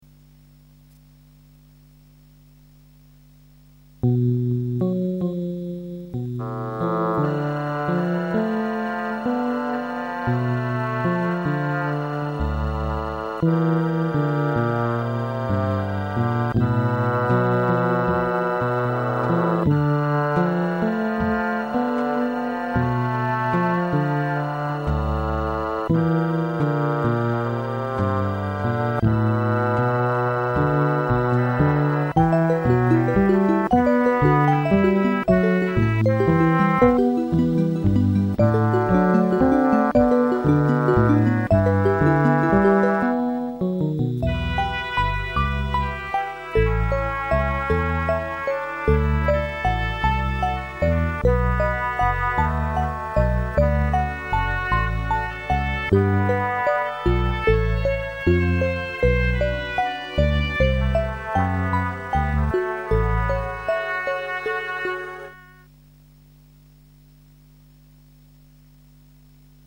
LucyTuned song